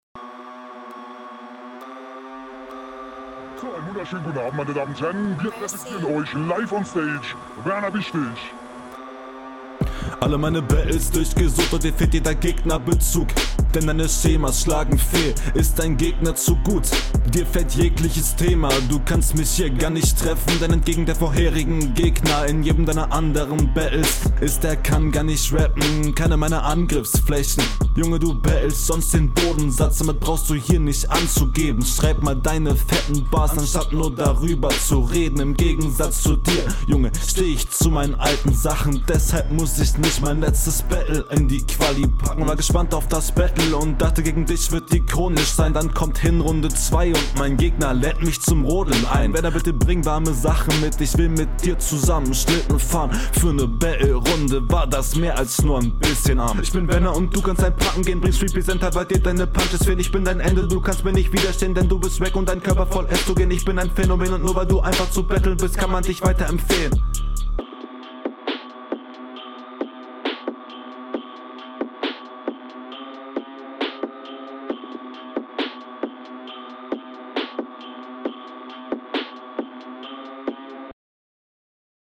- Unsicherheit auf dem Beat - Teilweise sehr offbeat - Leider wenig gehaltvolle Konter